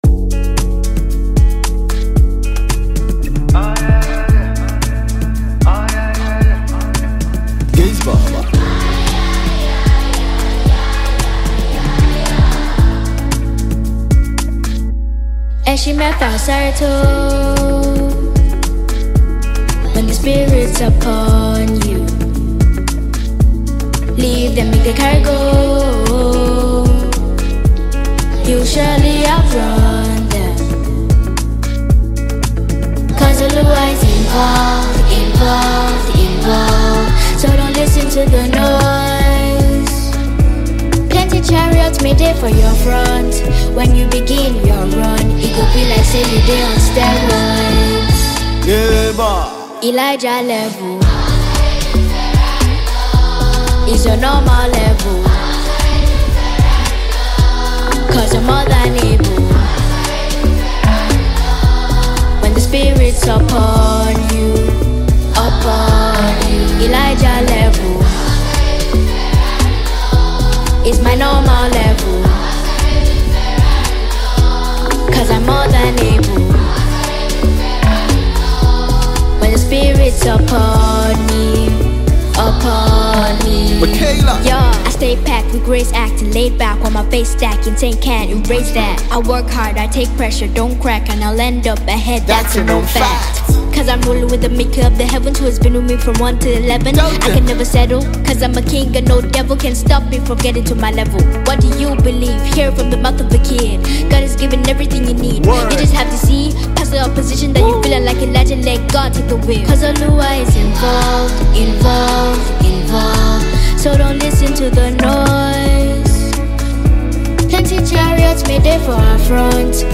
Mp3 Gospel Songs
incorporating catchy tunes and age-appropriate lyrics.